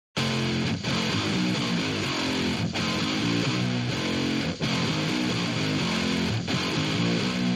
SLK_guitar_corn_cobb_4bar_128_F
guitar-orn.mp3